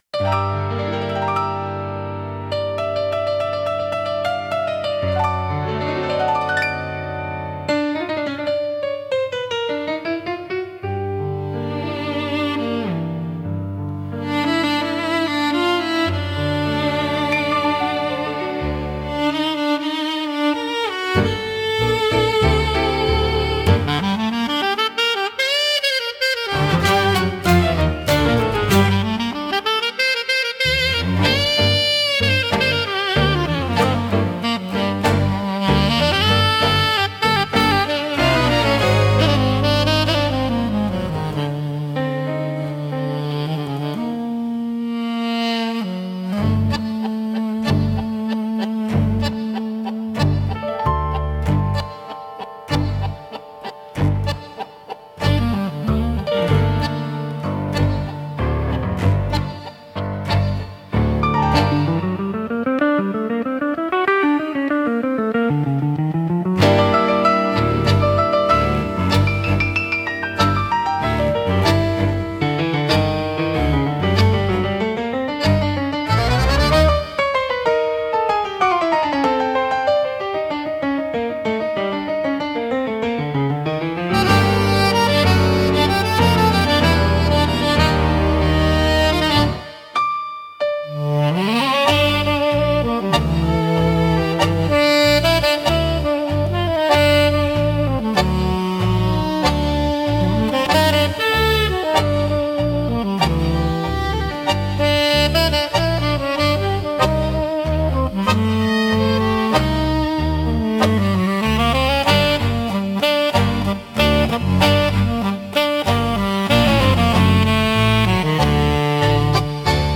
música e arranjo: IA) instrumental